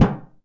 metal_tin_impacts_deep_03.wav